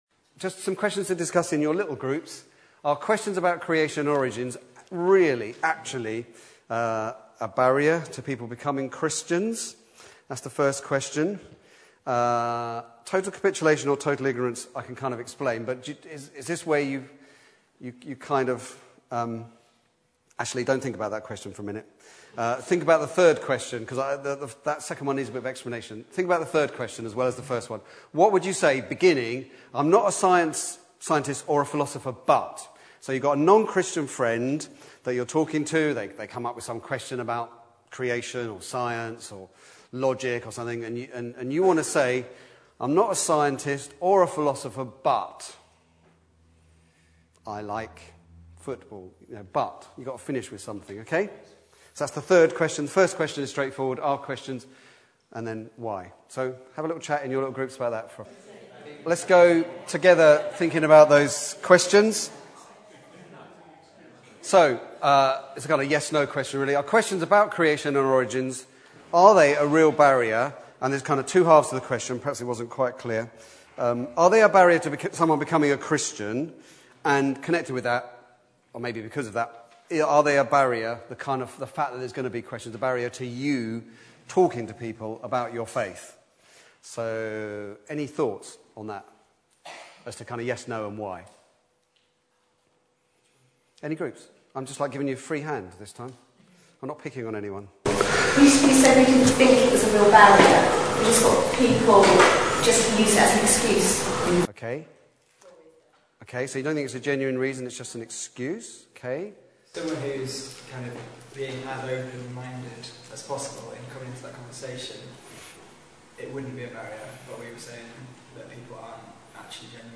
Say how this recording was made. at Christ Church on Sunday evening 27th March 2017